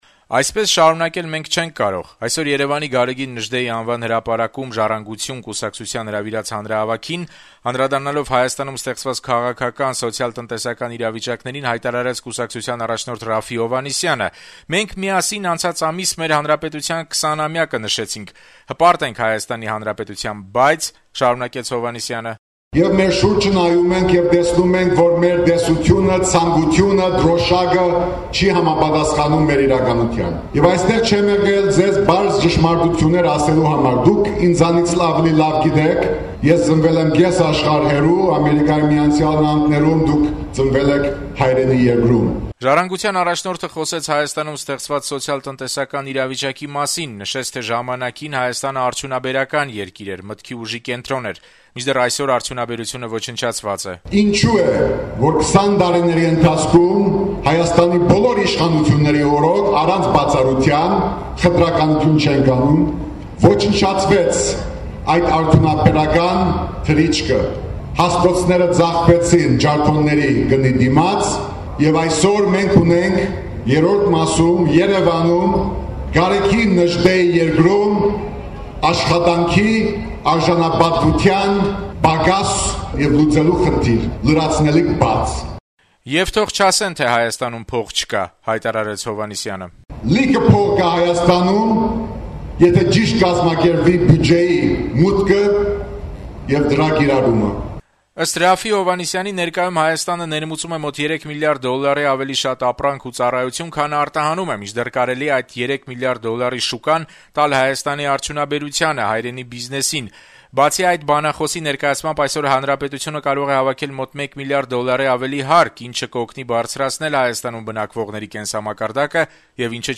«Ժառանգություն»-ը հանրահավաք անցկացրեց Գարեգին Նժդեհի հրապարակում: